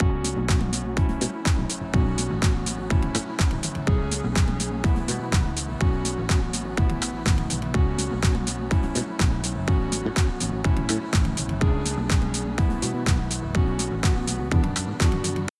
Here’s a second null test, with different audio!